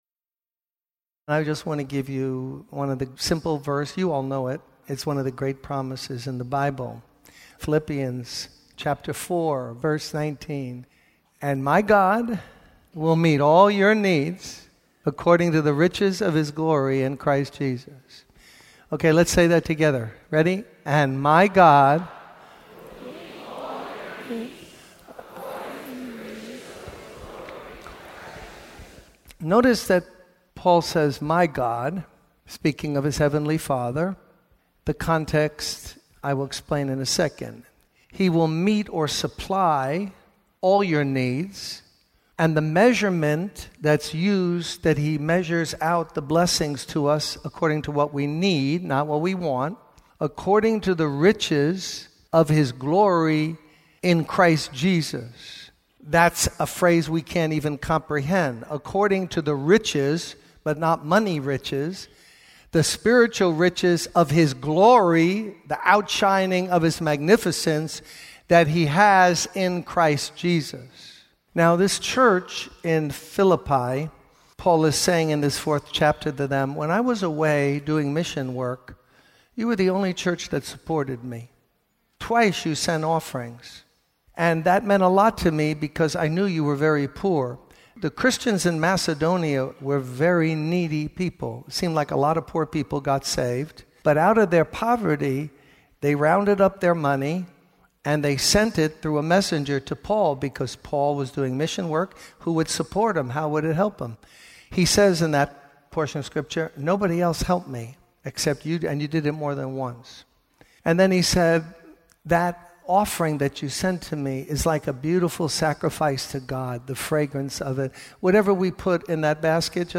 In this sermon, the speaker emphasizes the importance of giving for the work of the Lord.